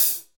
HH HH298.wav